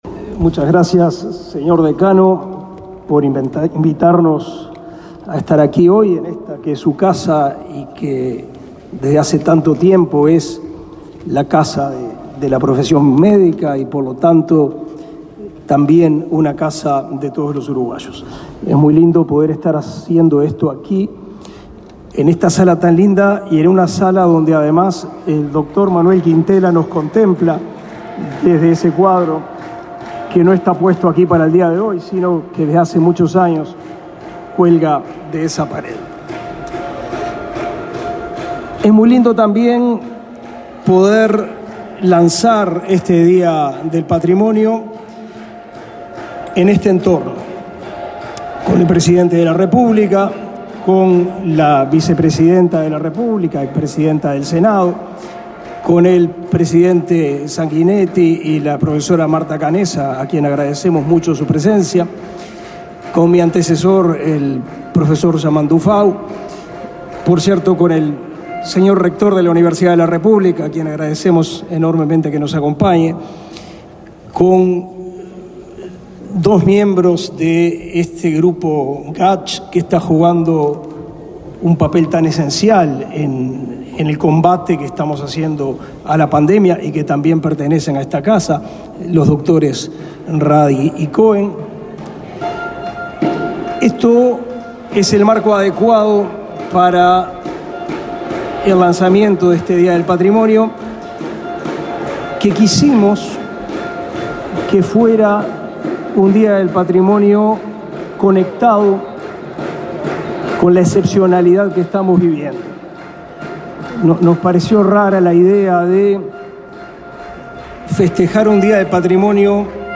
Bajo el lema “Medicina y salud, bienes a preservar”, la actividad homenajeará al médico Manuel Quintela, decano de la Facultad de Medicina e impulsor de la creación del Hospital de Clínicas. El ministro Pablo da Silveira destacó la figura de Quintela.